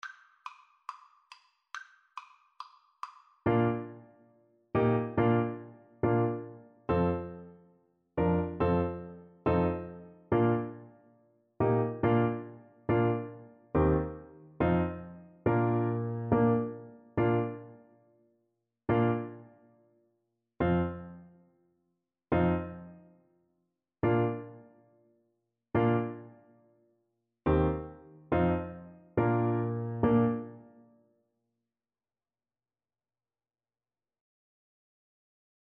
4/4 (View more 4/4 Music)
Fast = c. 140